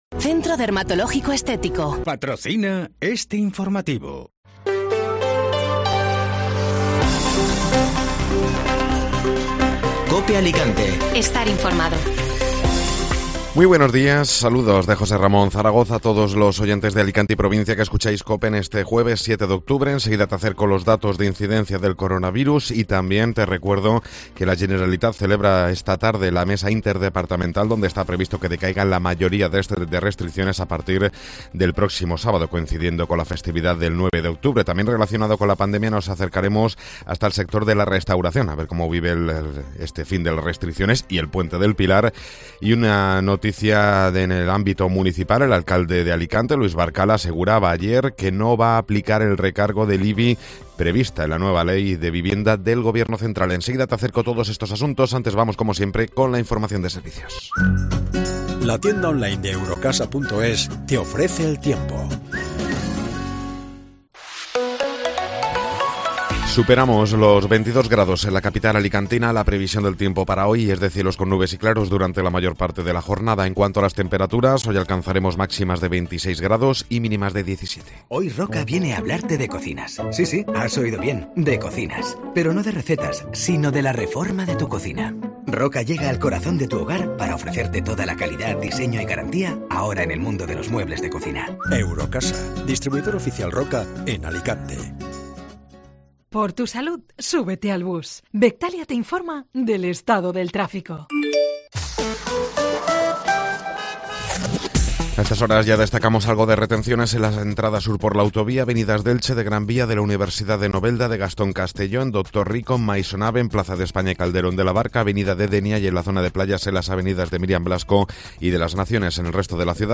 Informativo Matinal (Jueves 7 de Octubre)